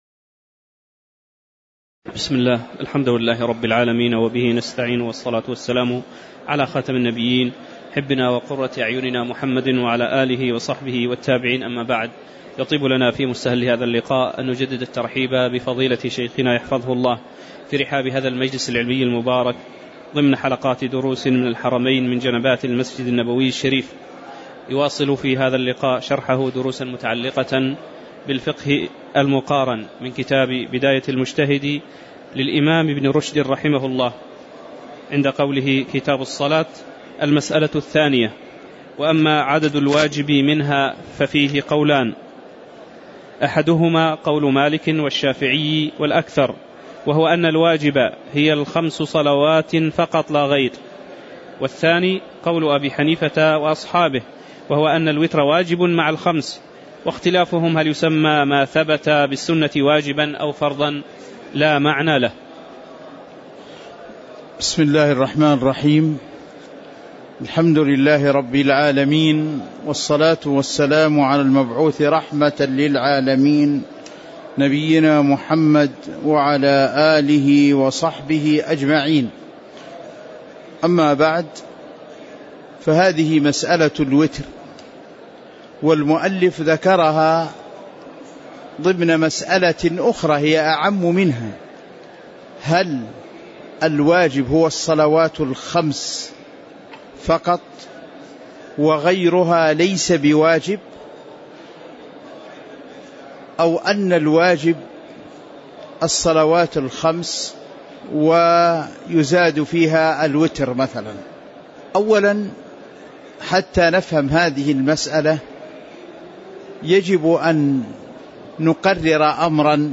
تاريخ النشر ١٧ محرم ١٤٤١ هـ المكان: المسجد النبوي الشيخ